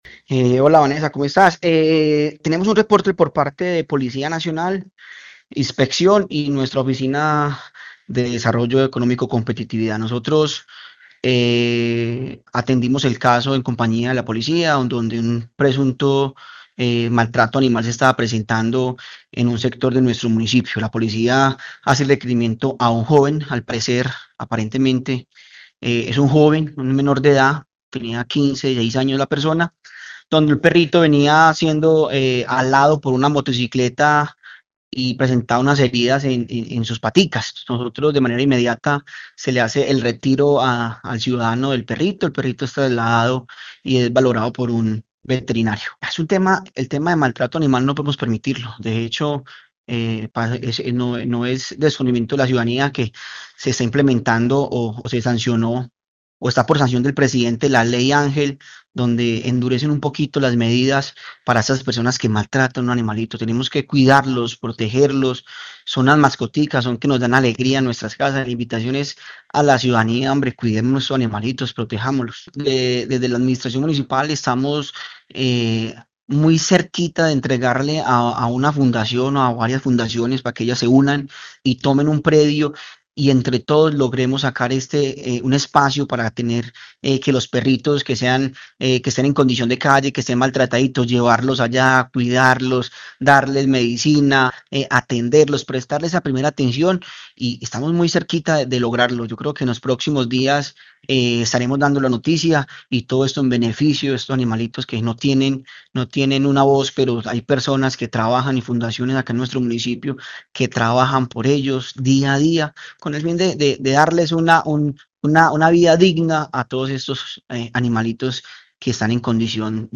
Nicolas Alvarez, secretario de Gobierno de Quimbaya